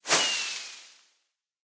fireworks
launch1.ogg